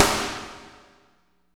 48.03 SNR.wav